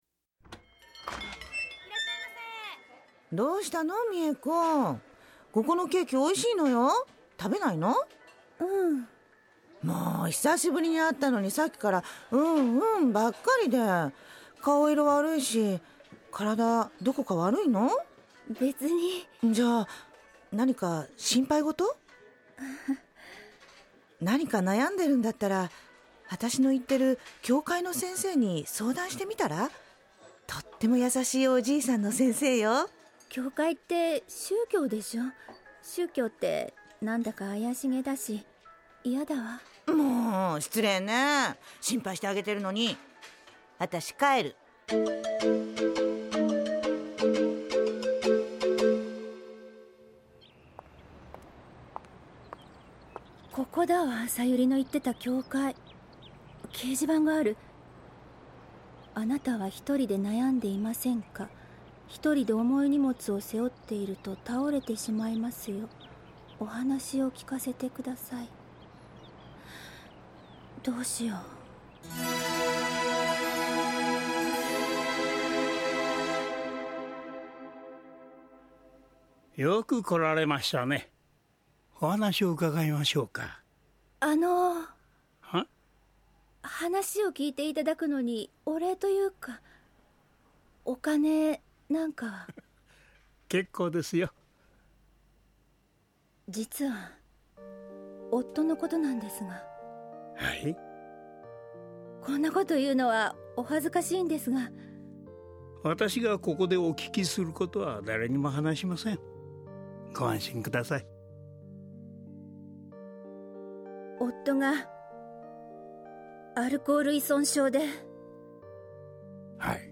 ラジオドラマ「ようお参りです」第２回「私だけ、どうして…」
・教会の先生（70歳・男性）